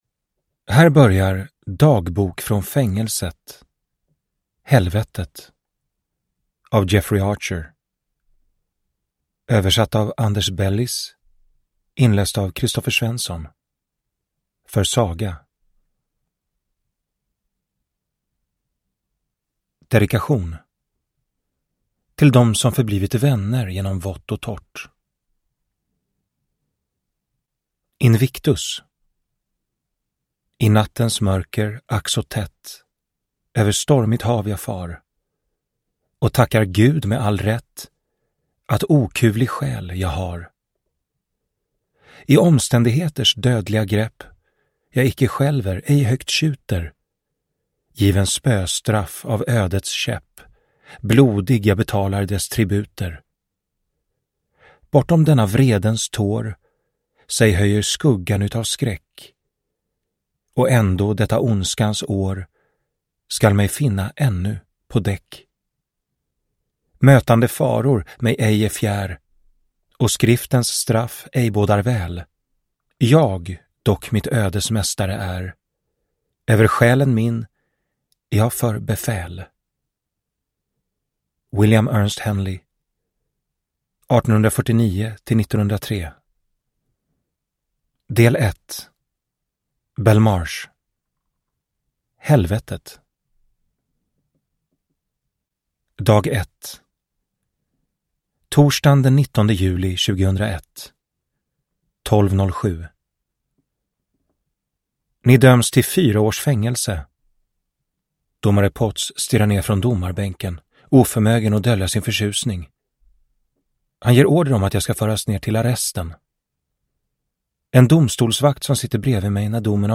Dagbok från fängelset - Helvetet (ljudbok) av Jeffrey Archer